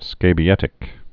(skābē-ĕtĭk)